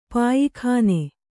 ♪ pāyikhāne